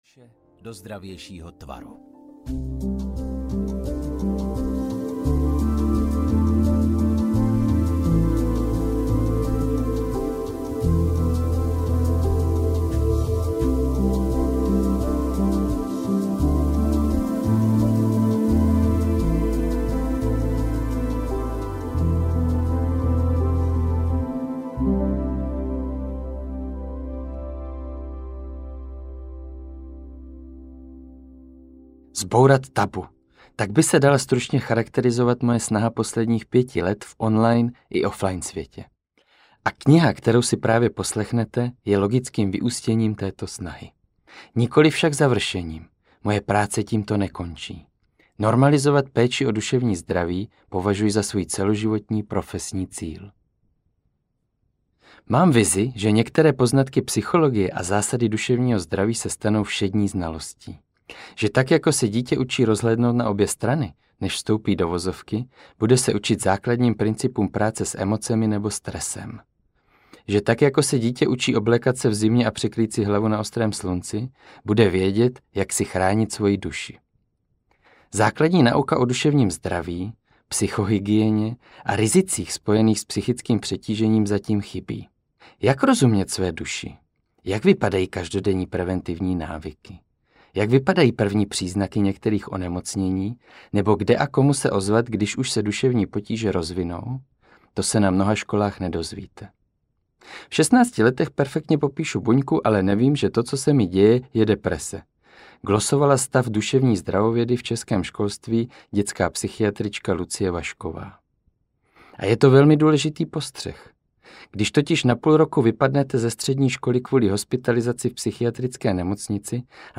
Mozaika duše audiokniha
Ukázka z knihy